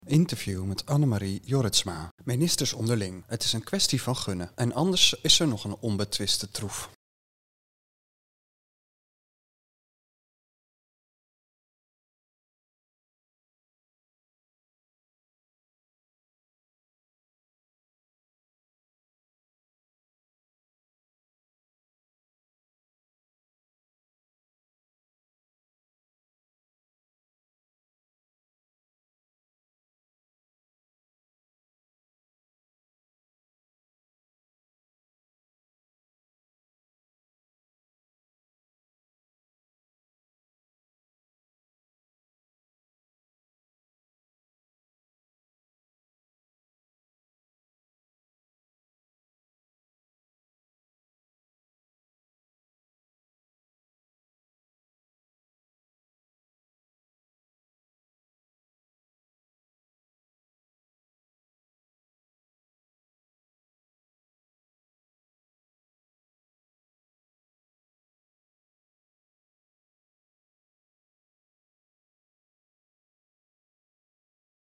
Interview met Annemarie Jorritsma